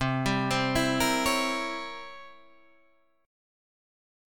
C7b9 chord